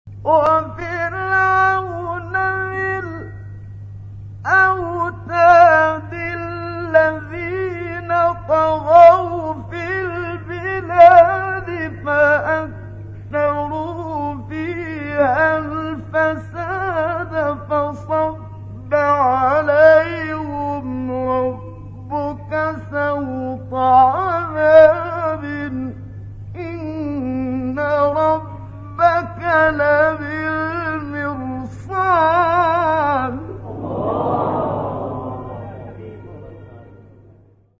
گروه فعالیت‌های قرآنی: مقاطع صوتی با صدای قاریان ممتاز کشور مصر را می‌شنوید.